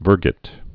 (vûrgĭt)